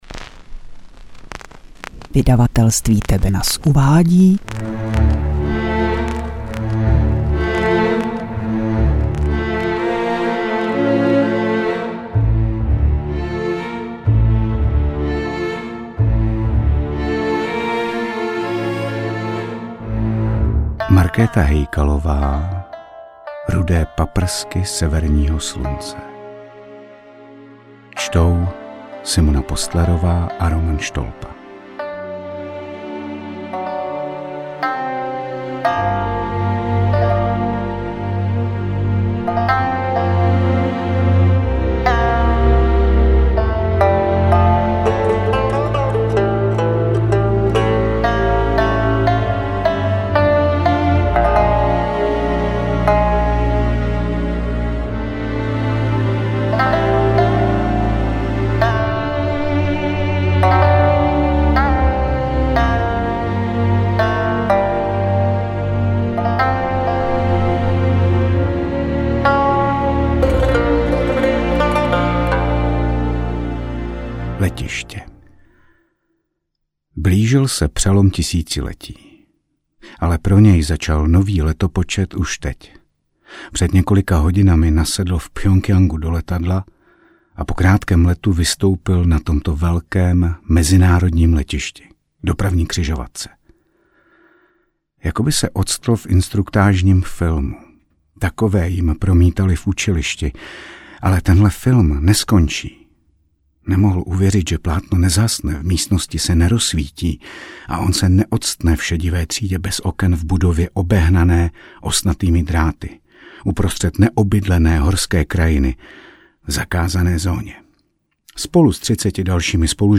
AudioKniha ke stažení, 36 x mp3, délka 7 hod. 47 min., velikost 642,4 MB, česky